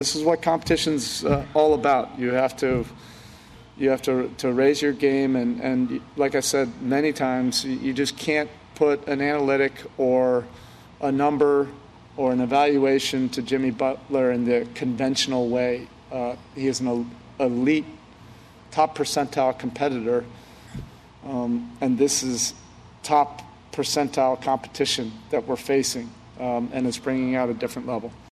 Heat head coach Erik Spoelstra tried to keep things in perspective Sunday night.